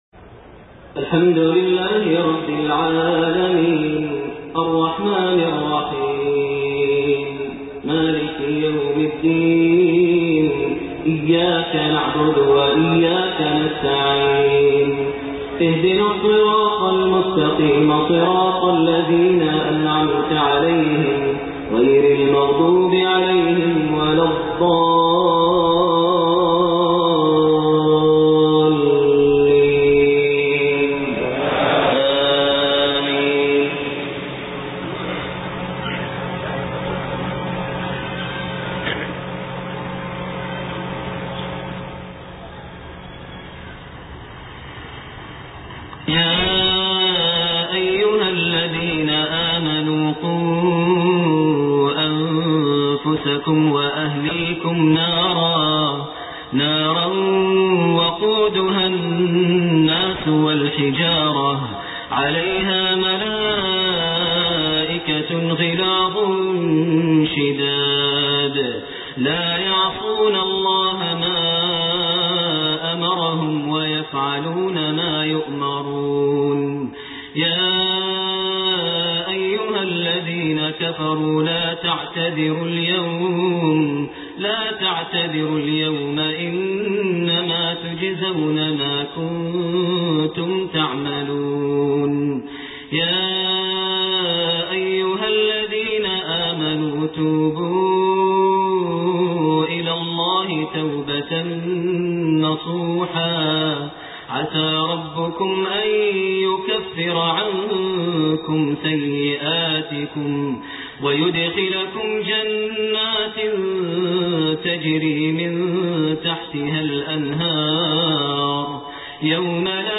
Isha prayer surah At-Tahrim > 1429 H > Prayers - Maher Almuaiqly Recitations